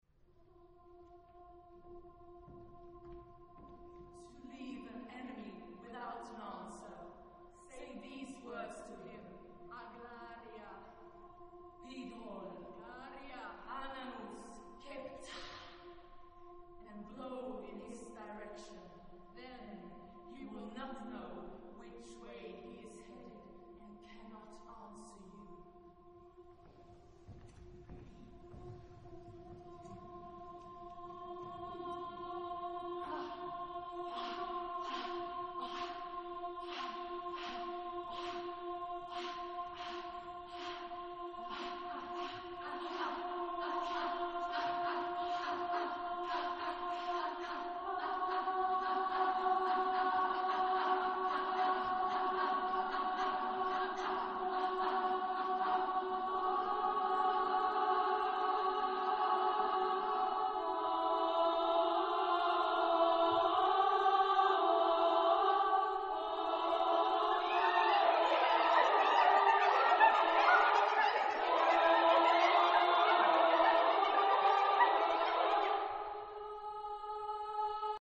Genre-Style-Forme : Avant-garde ; Profane
Type de choeur : SSAA  (4 voix égales de femmes )
Solistes : Sprecher (1)
Tonalité : clusters ; libre